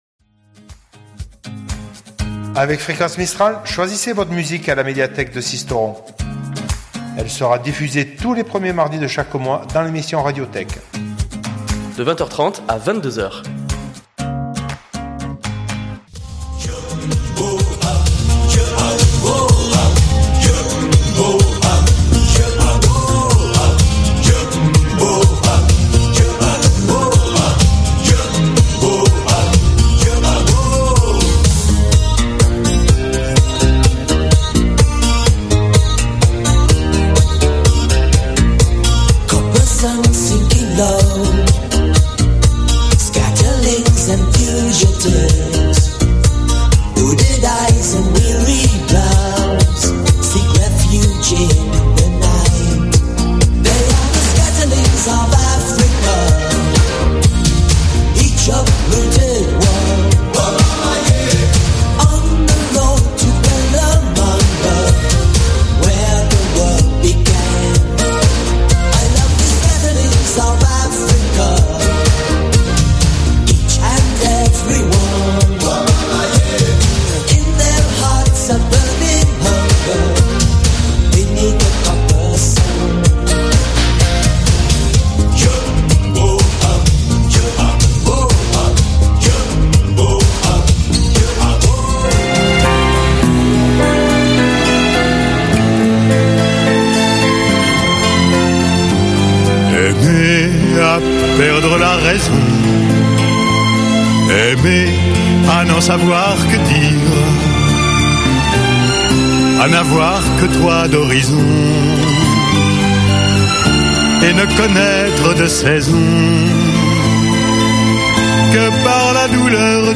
(63.96 Mo) Le rendez-vous incontournable tous les premiers mardi du mois sur le 99.2, ça continue en 2019 !